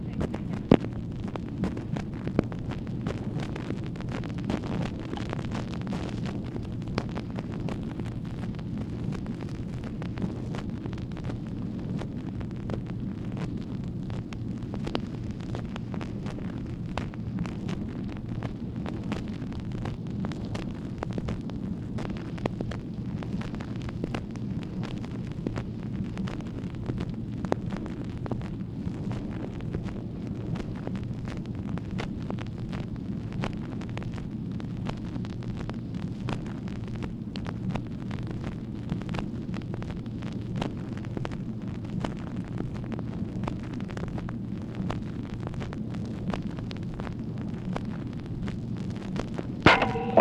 MACHINE NOISE, August 7, 1964
Secret White House Tapes | Lyndon B. Johnson Presidency